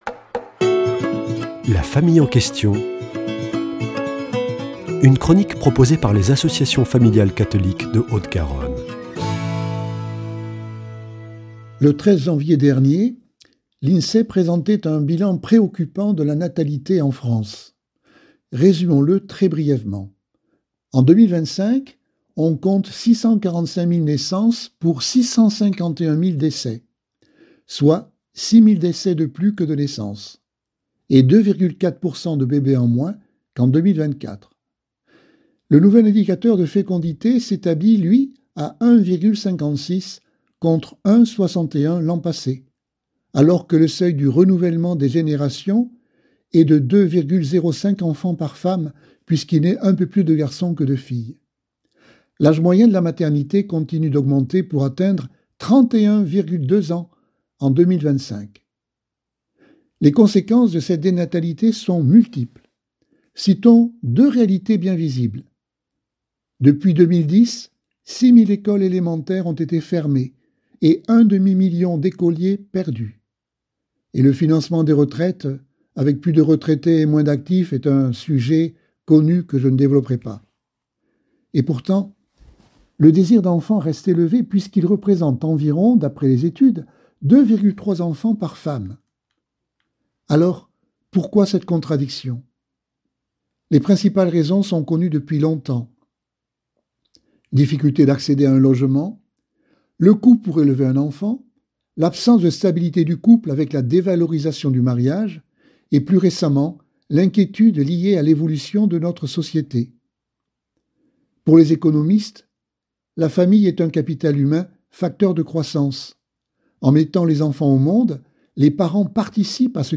mercredi 28 janvier 2026 Chronique La famille en question Durée 3 min